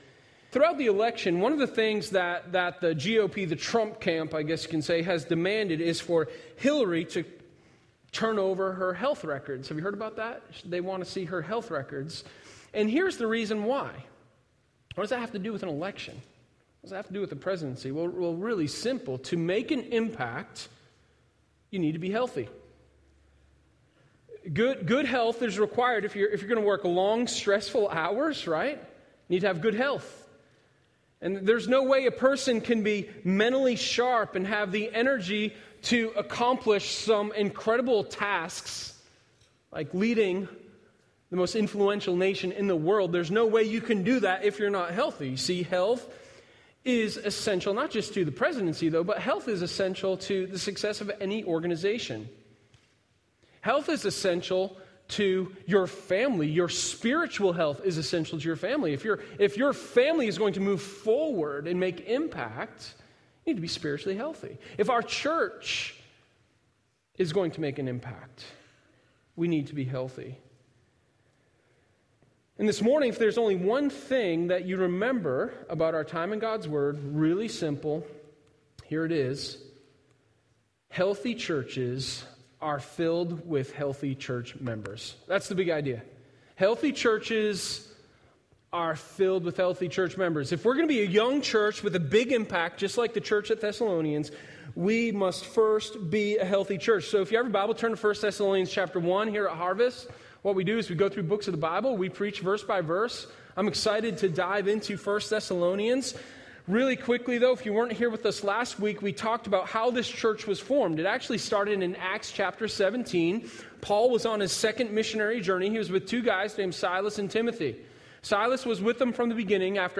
Sermon1023_29MarksofaHealthyChurch.mp3